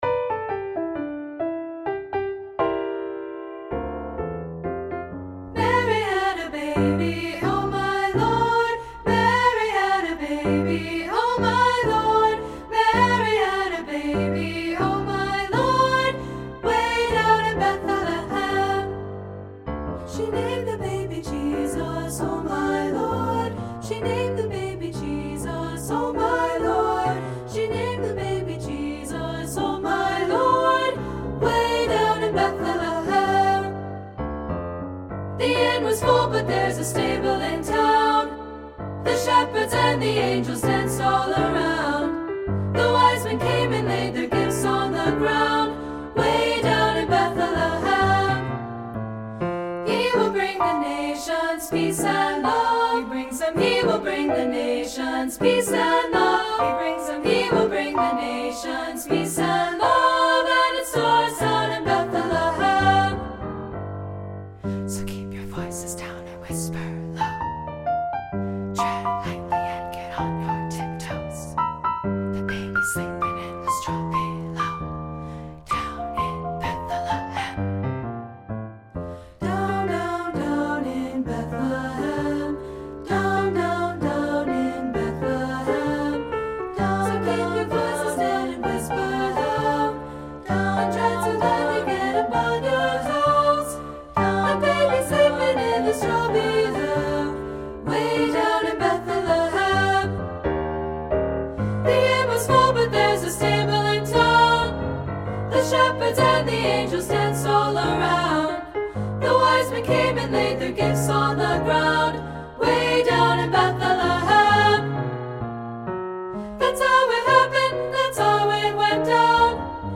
SA Voices with Piano
• Piano
• Soprano
• Alto
Studio Recording
Ensemble: Treble Chorus
Key: G major
Tempo: Swing it! (q = 130)
Accompanied: Accompanied Chorus